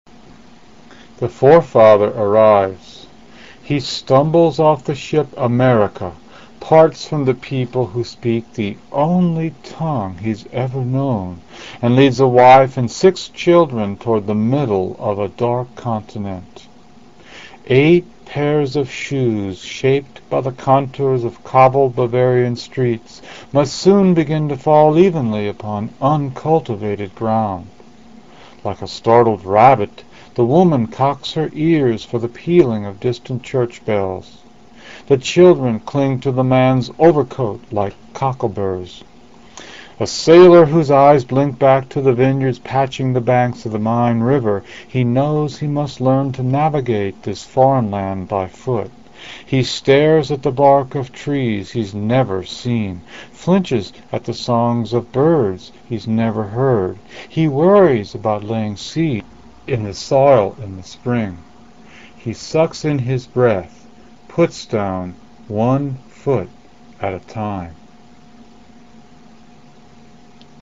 Read by Norbert Krapf